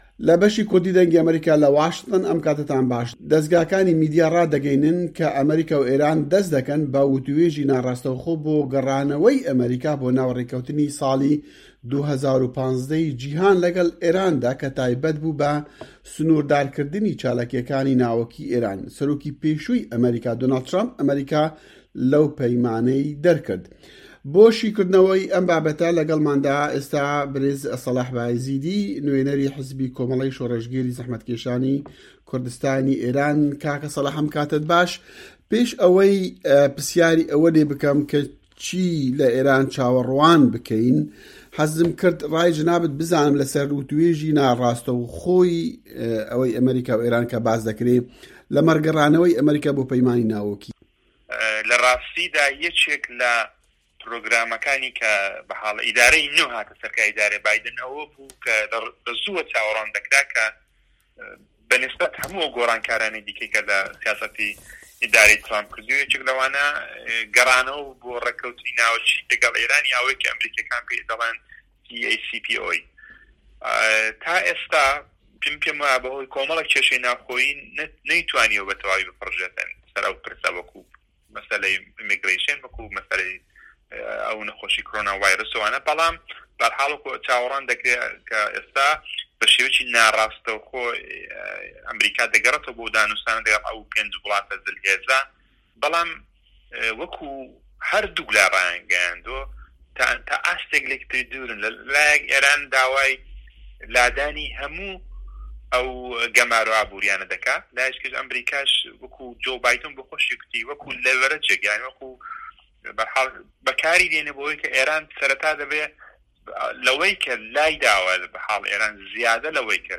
وتووێژی ناڕاستەوخۆی ئێران و ئەمەریکا